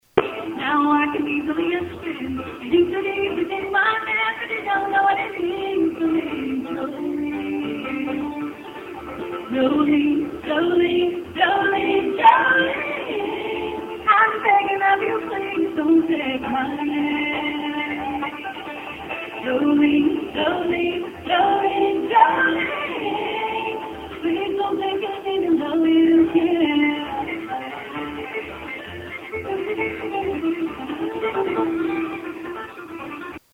Cellphone Quality Again